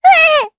Estelle-Vox_Damage_jp_3.wav